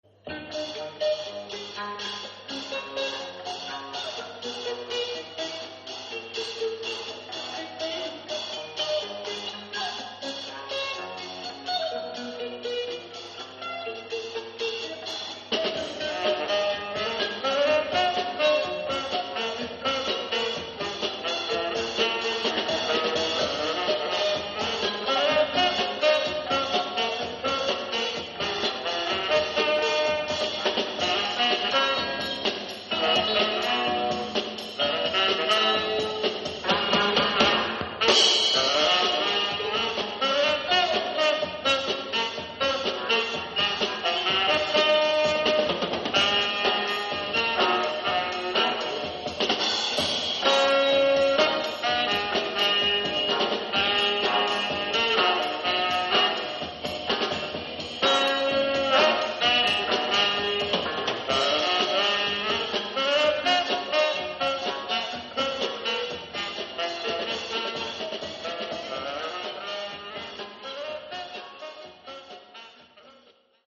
Guitar/Vocals
Tenor Sax/Vocals
Keyboard
Bass
Drums
a mixture of pop and jazz